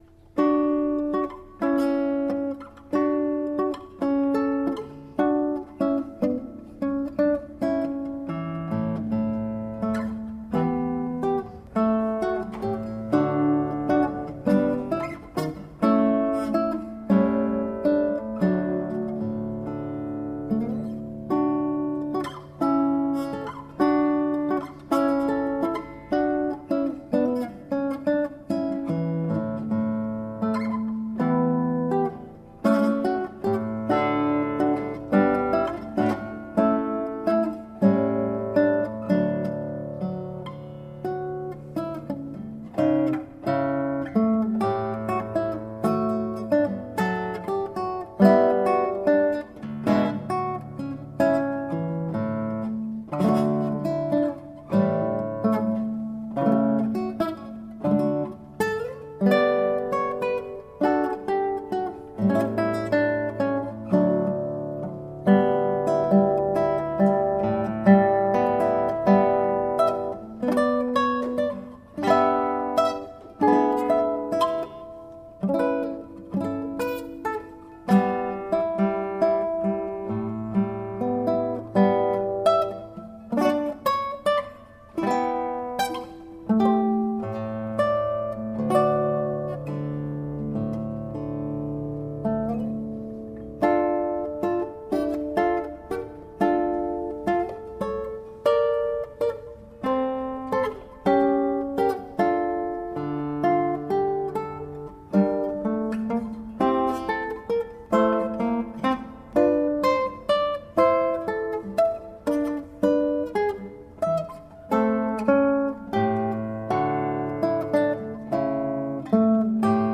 Solo pieces